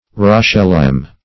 Search Result for " rochelime" : The Collaborative International Dictionary of English v.0.48: Rochelime \Roche"lime`\, n. [F. roche rock + E. lime.] Lime in the lump after it is burned; quicklime.